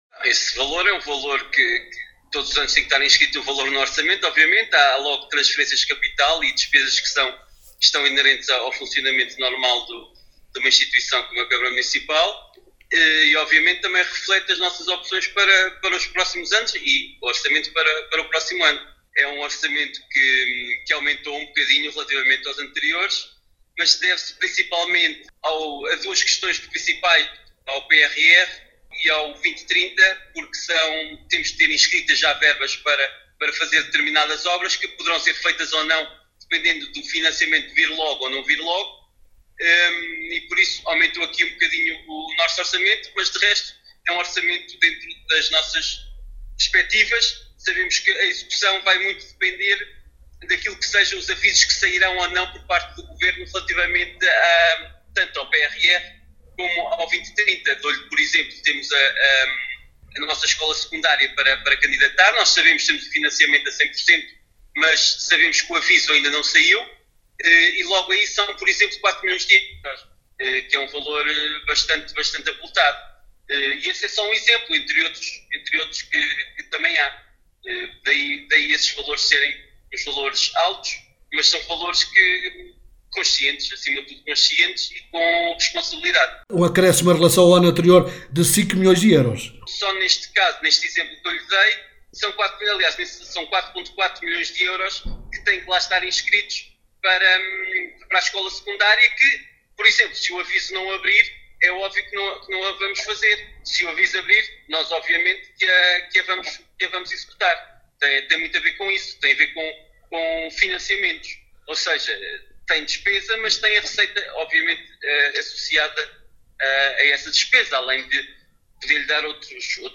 Paulo Marques, Presidente do Município de Vila Nova de Paiva, em declarações à Alive FM, fala deste orçamento, onde a prioridade para 2025, passa pela conclusão das obras de requalificação da Escola Básica 1, Aquilino Ribeiro, no valor de 1 milhão e 600 mil euros, entre outras…